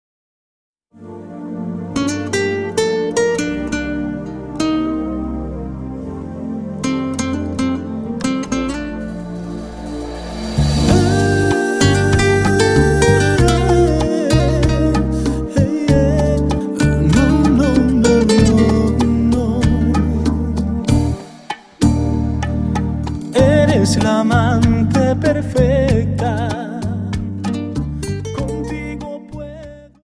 Dance: Rumba 24 Song